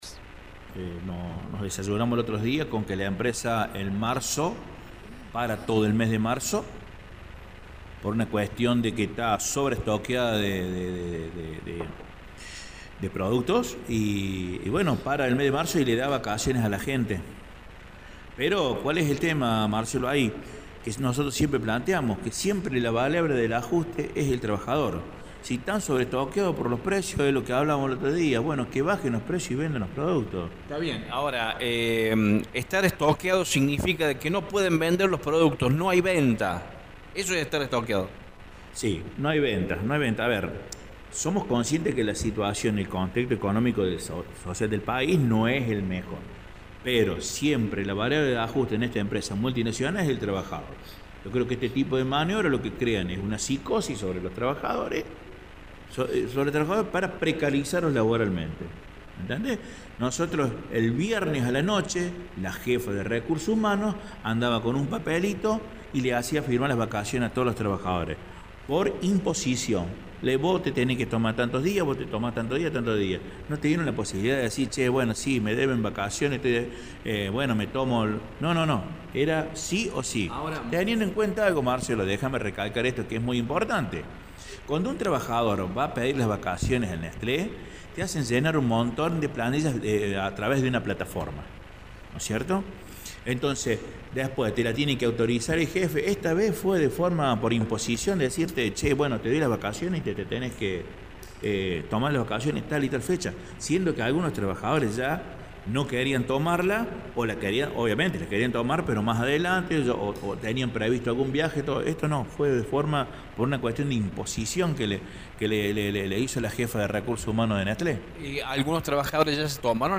en diálogo con Radio Show